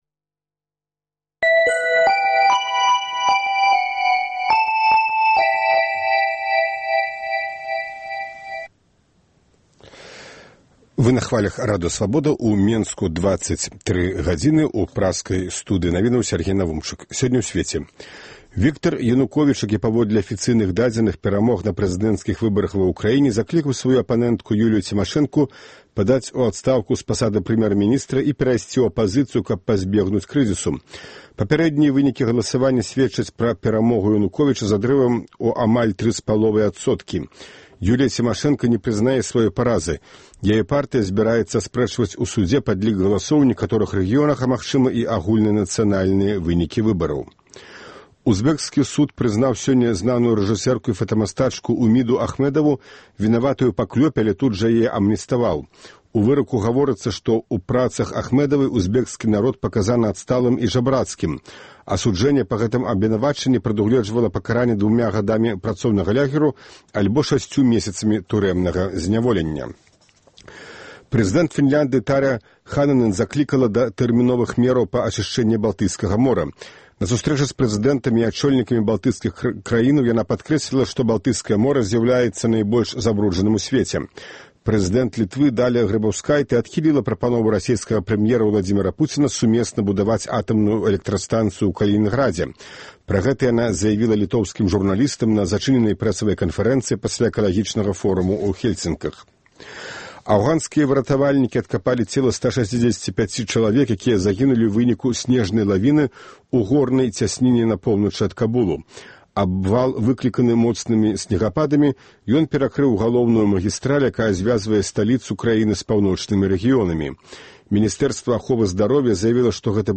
Навіны